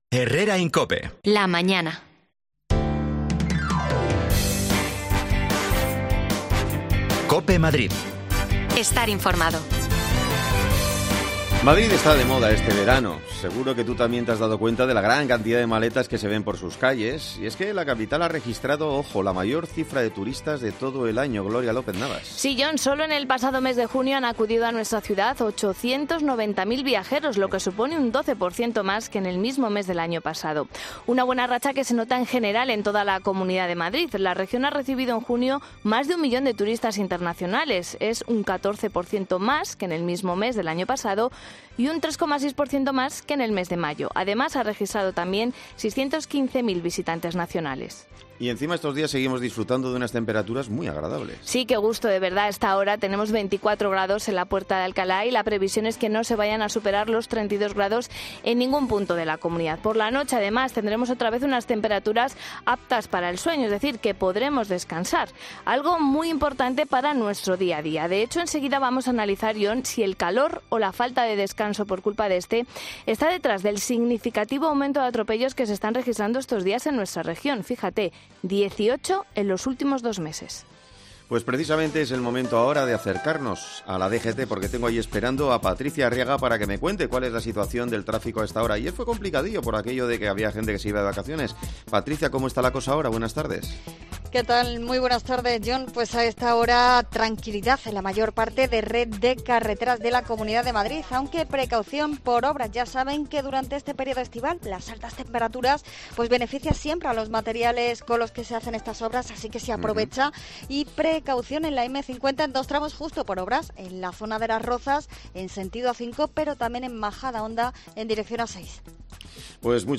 Suman ya 18 los atropellos en Madrid en estos dos últimos meses. Analizamos con un experto cuales pueden ser las causas de ello y porqué sigue creciendo el número
Las desconexiones locales de Madrid son espacios de 10 minutos de duración que se emiten en COPE, de lunes a viernes.